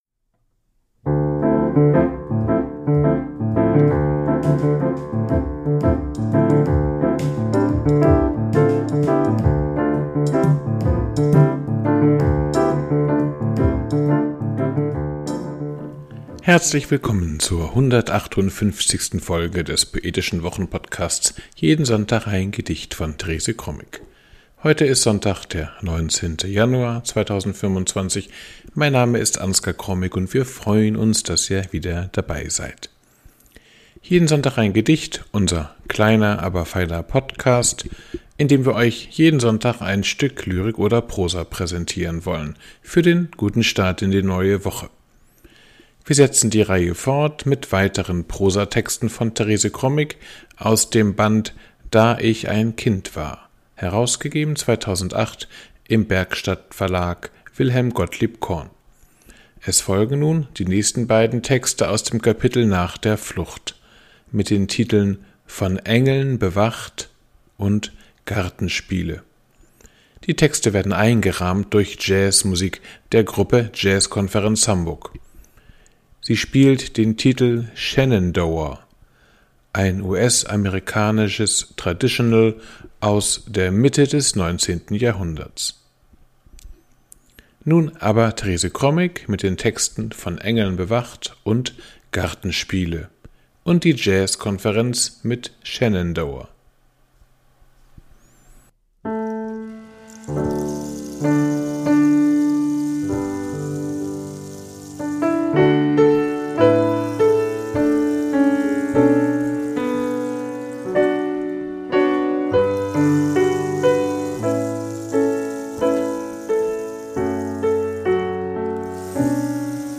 Sie hören die Texte "Von Engeln bewacht" und "Gartenspiele"Kuchen backen" auf den Seiten 20-22 , Die Jazzkonferenz spielt den Titel "Shenandoha" ein US amerikanisches Traditional aus der Mitte des 19. Jahrhunderts.